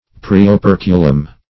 Search Result for " praeoperculum" : The Collaborative International Dictionary of English v.0.48: Praeoperculum \Pr[ae]`o*per"cu*lum\, n. [NL.]